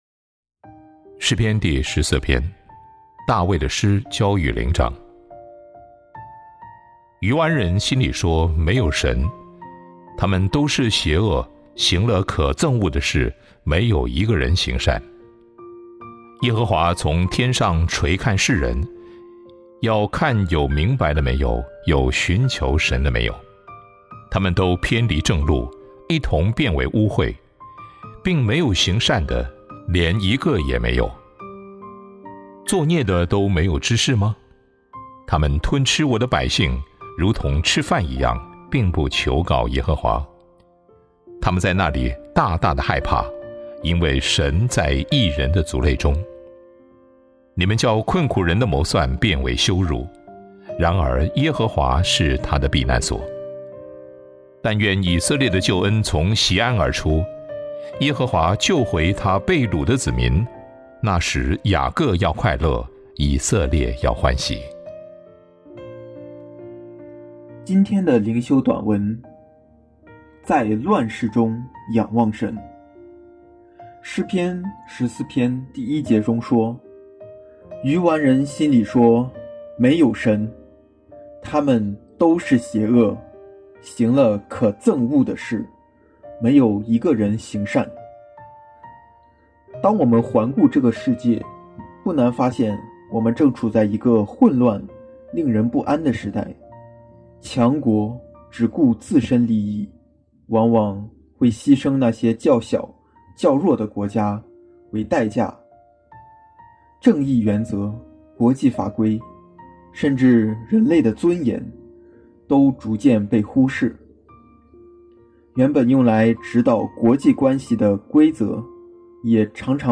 灵修分享